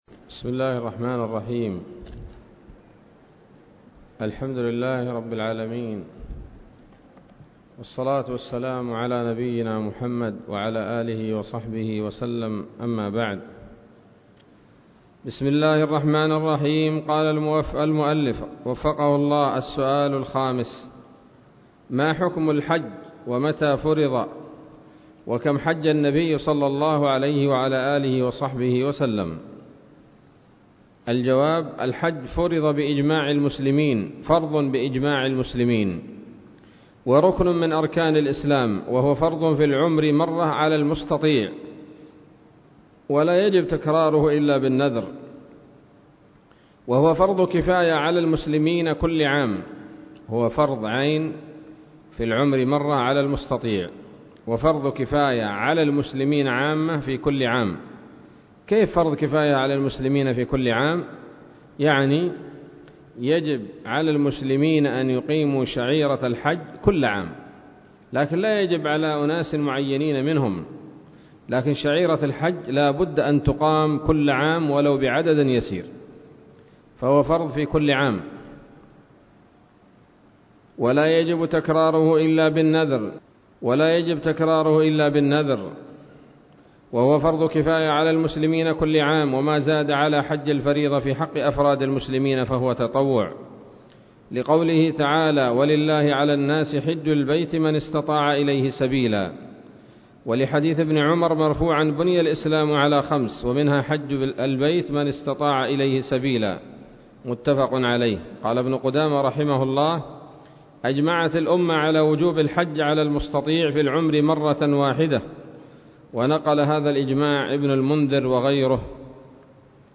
الدرس العشرون من شرح القول الأنيق في حج بيت الله العتيق